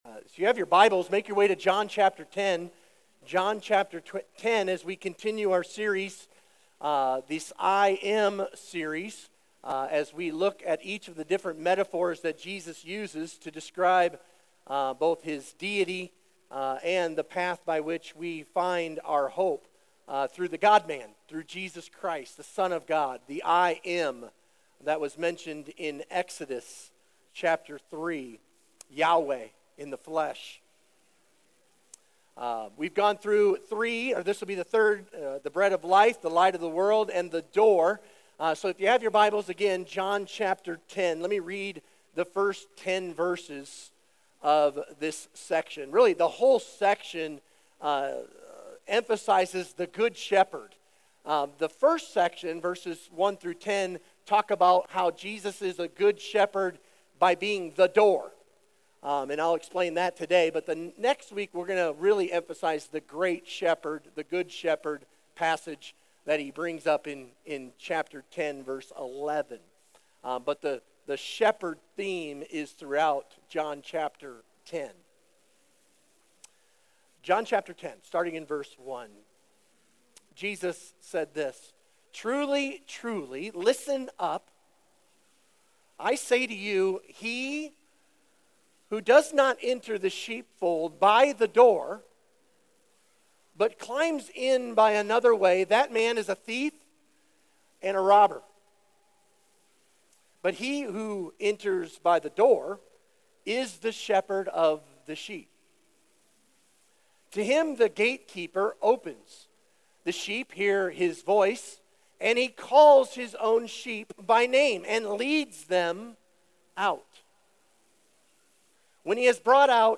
Sermon Questions Read John 9:13-10:10.